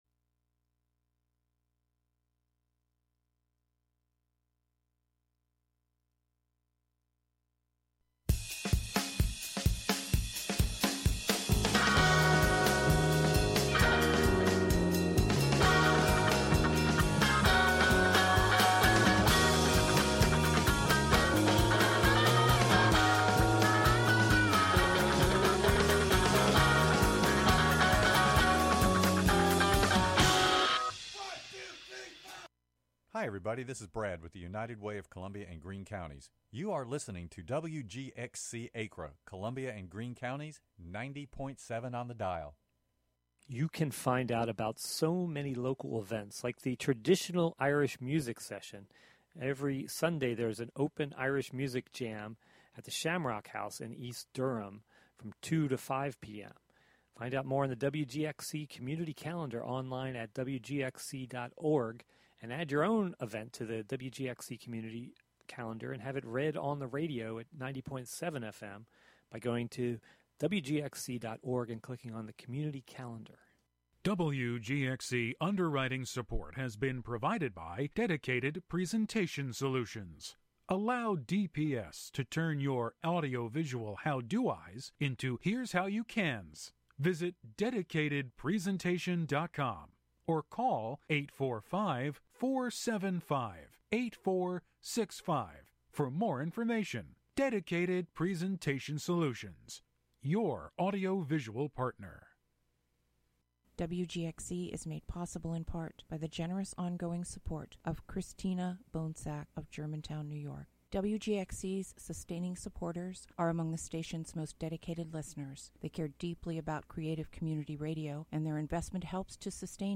The show is broadcast live from Catskill, NY.